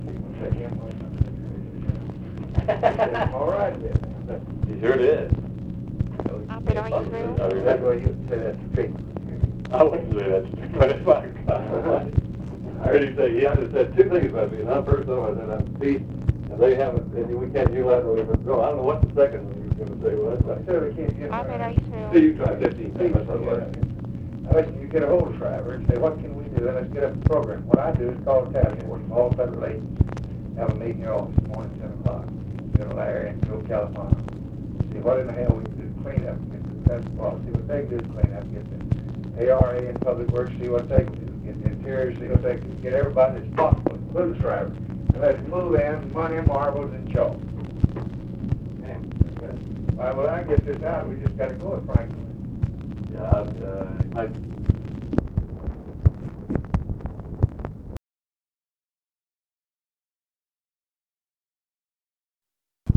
OFFICE CONVERSATION, August 20, 1965
Secret White House Tapes